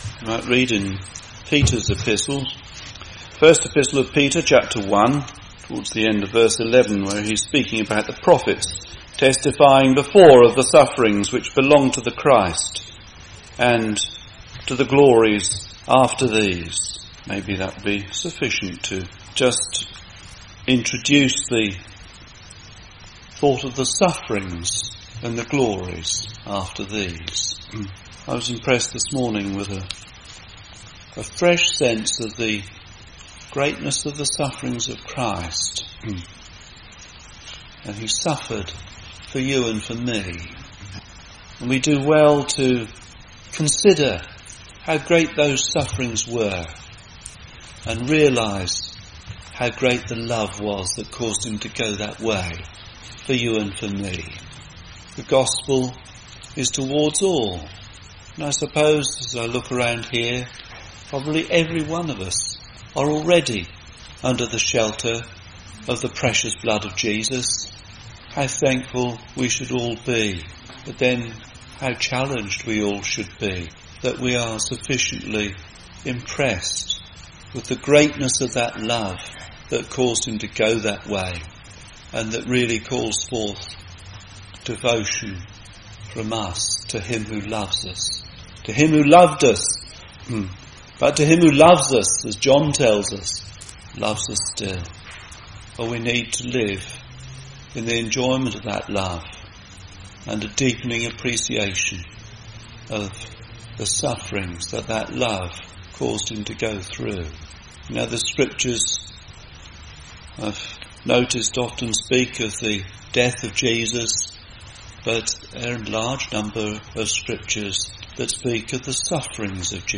In this Gospel preaching, you will be occupied with the sufferings of Christ. Jesus came into the world to save sinners and bring peace to mankind.